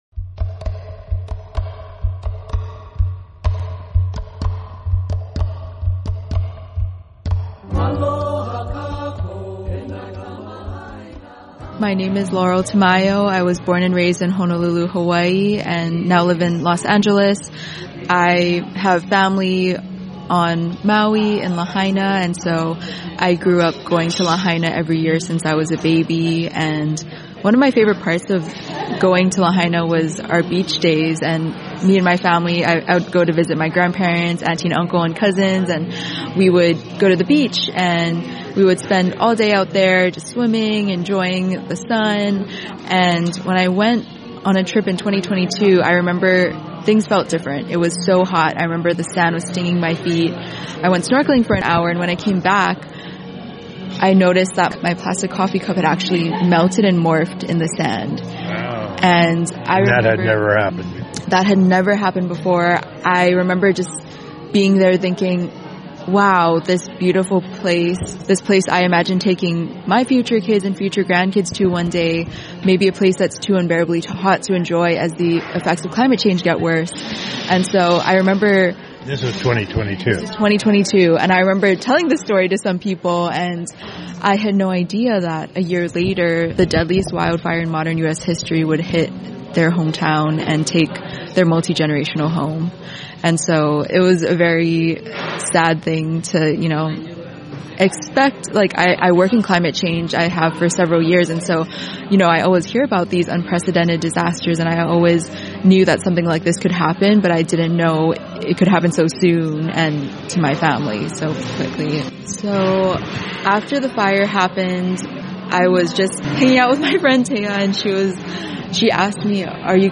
interviews are recorded live on location across America and around the world